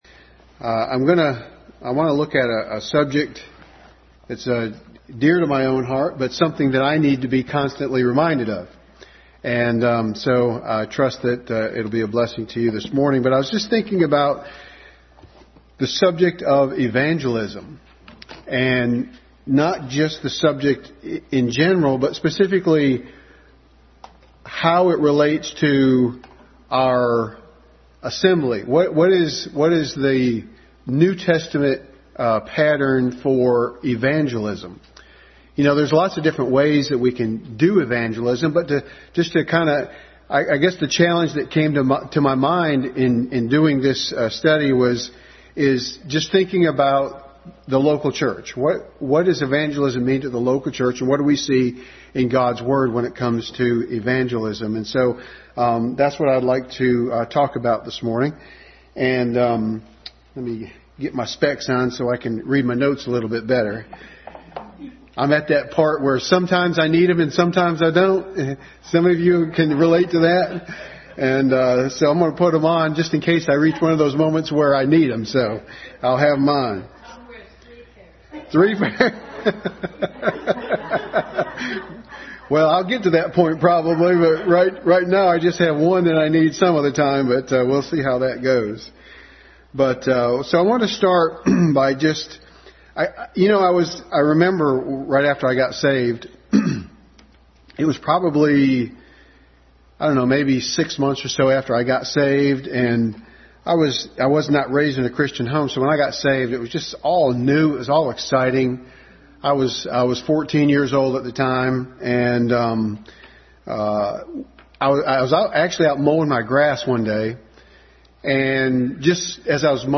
Adult Sunday School Class.